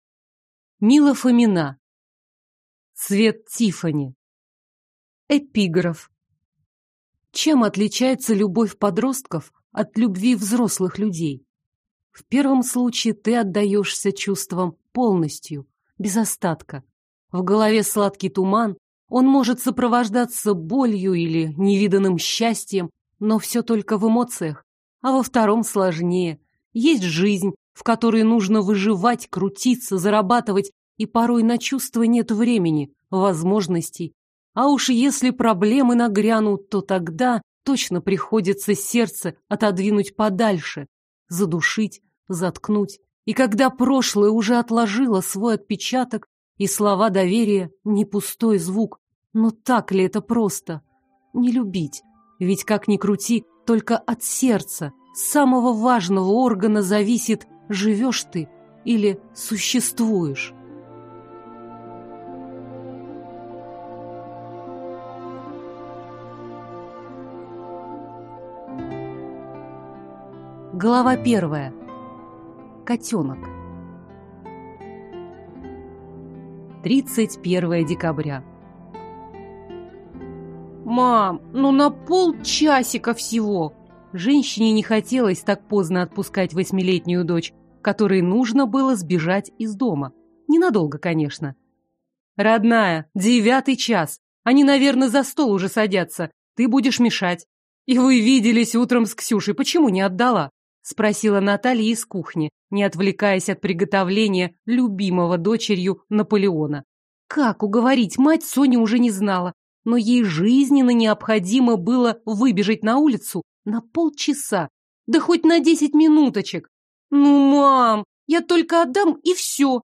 Аудиокнига Цвет Тиффани | Библиотека аудиокниг